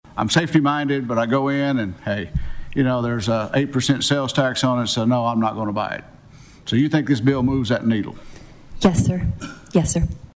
CLICK HERE to listen to commentary from Senator Roger Thompson.